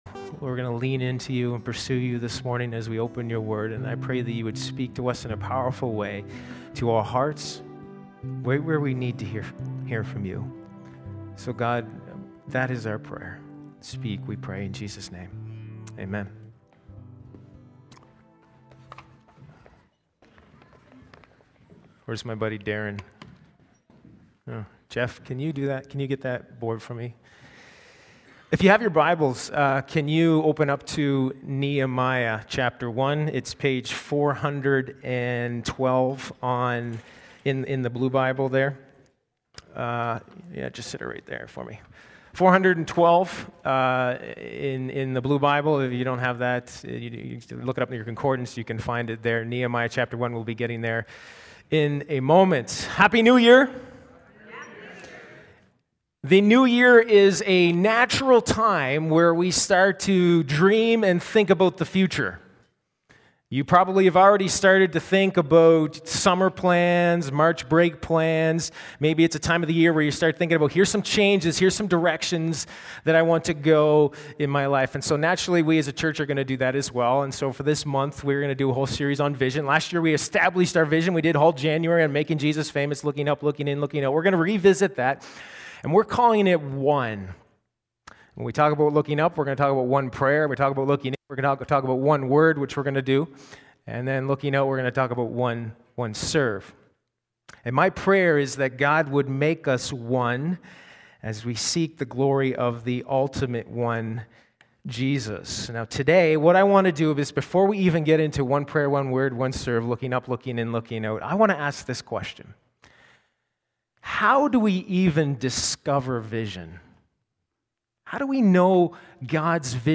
Sermons | Sturgeon Alliance Church
Guest Speaker